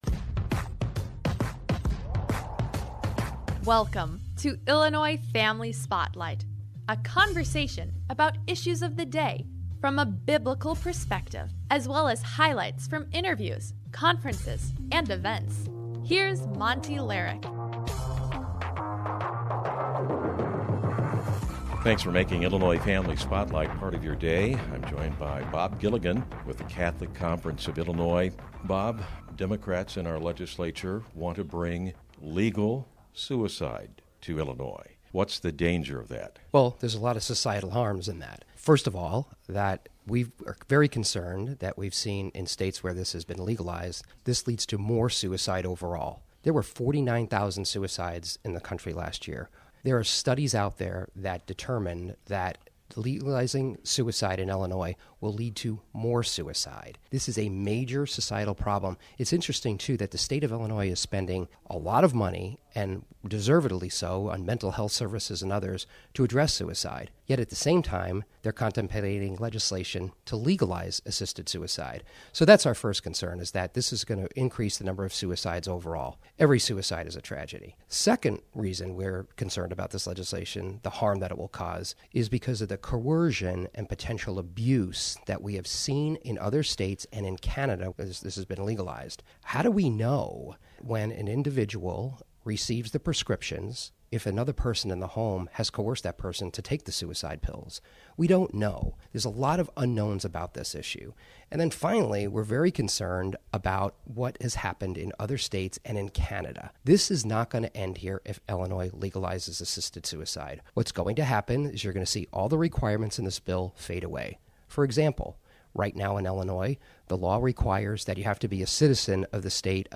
outside the Chicago hearing of pro-suicide legislation.